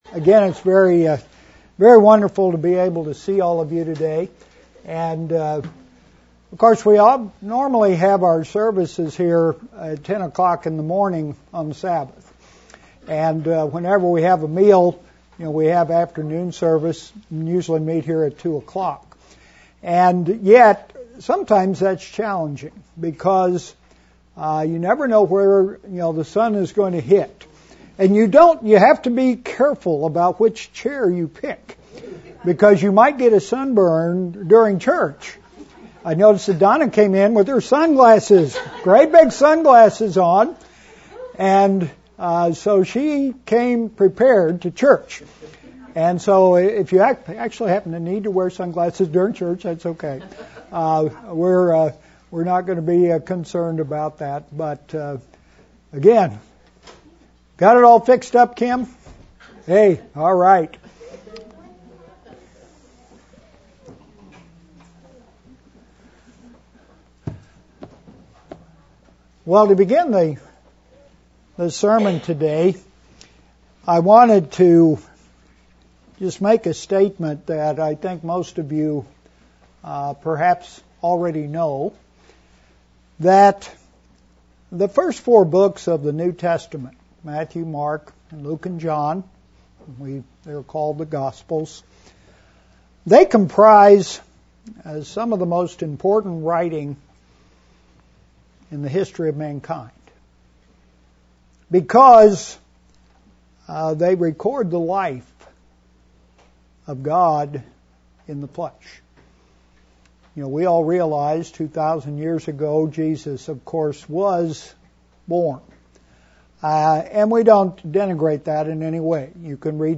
Christ reveals what that person will be like and the impact on the world of His Converted Ones. gospel message Sermon on the Mount Transcript This transcript was generated by AI and may contain errors.